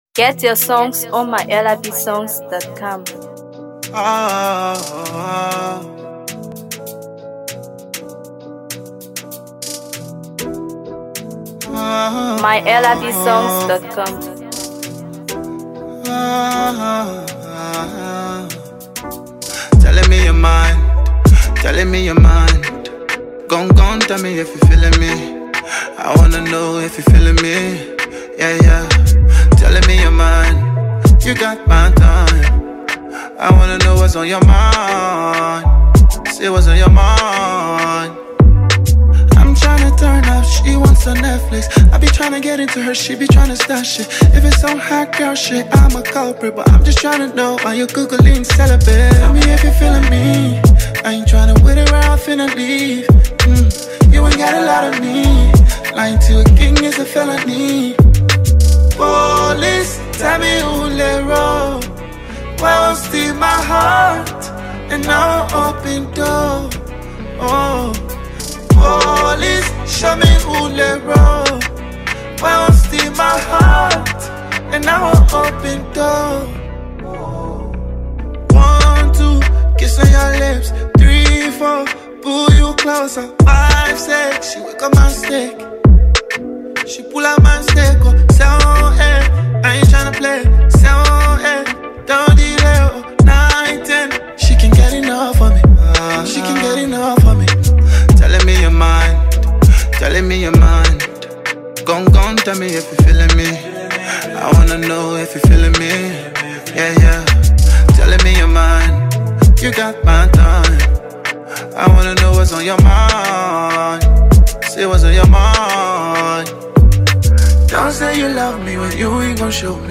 Afro PopMusic
wrapped in a rhythmic Afrobeat groove